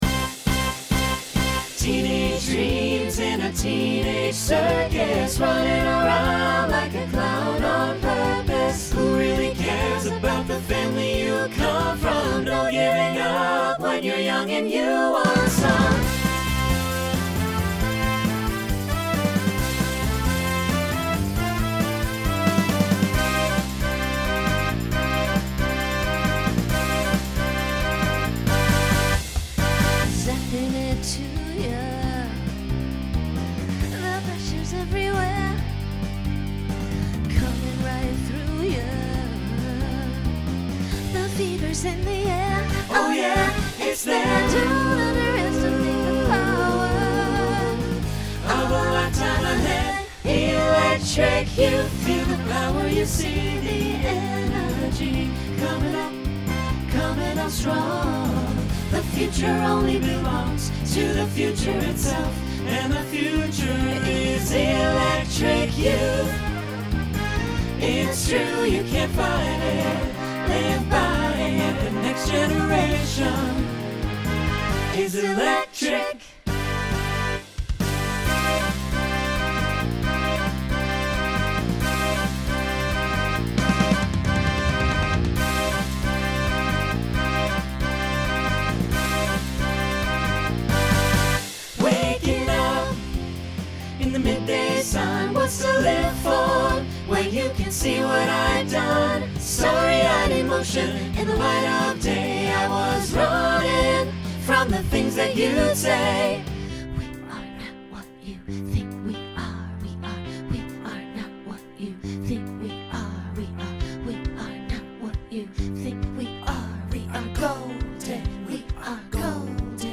Genre Pop/Dance
Opener Voicing SATB